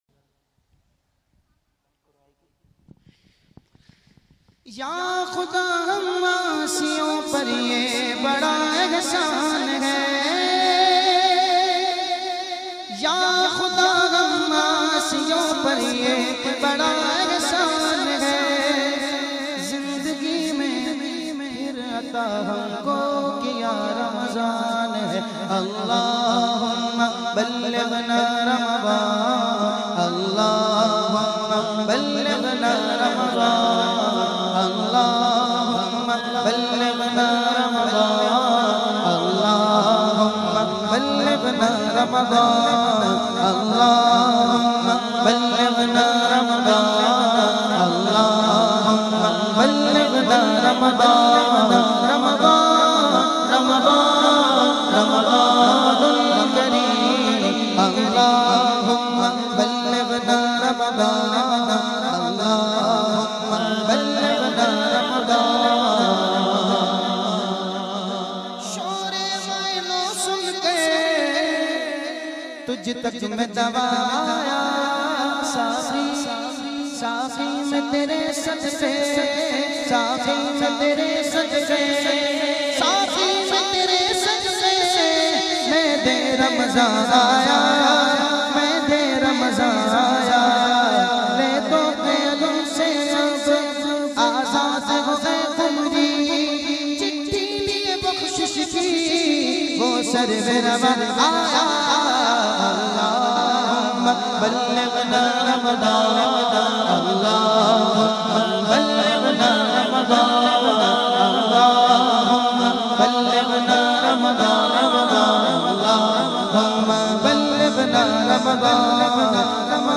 in a Heart-Touching Voice
is a distinguished Islamic scholar and naat khawan.